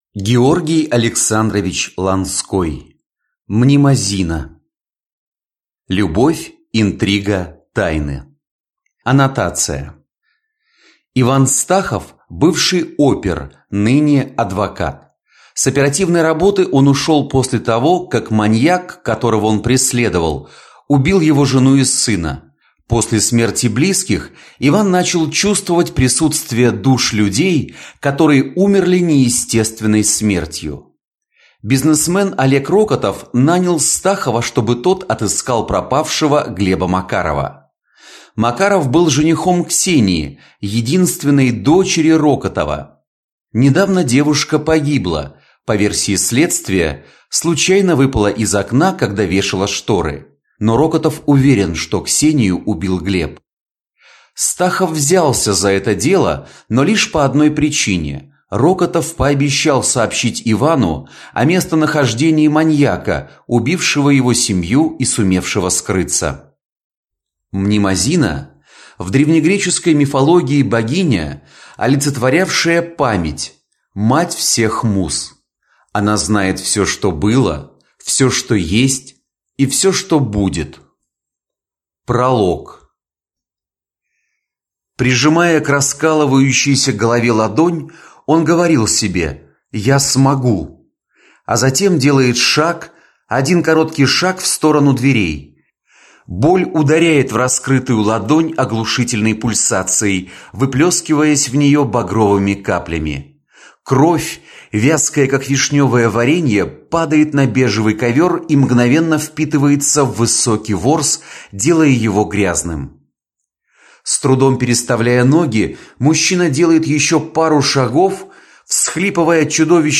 Аудиокнига Мнемозина | Библиотека аудиокниг